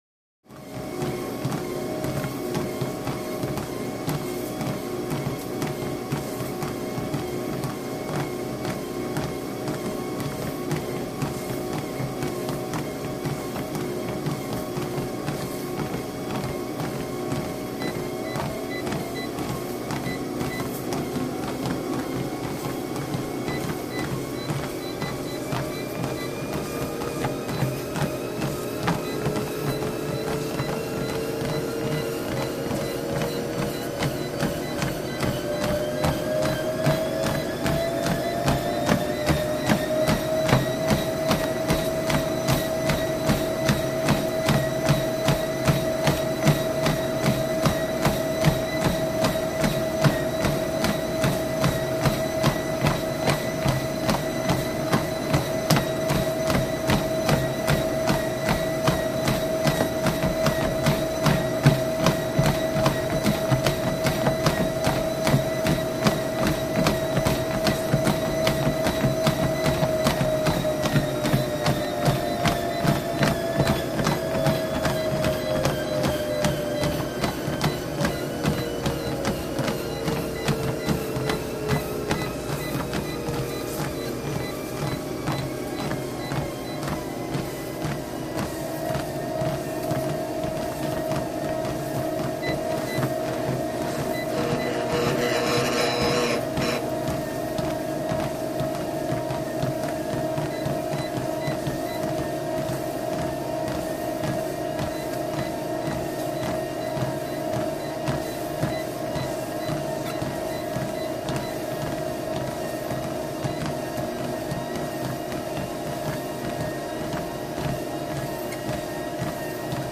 TreadmillStartJog WES030401
Cardiovascular Exercise; Treadmills In Progress, From Jogging To Running To Walking. Three Joggers.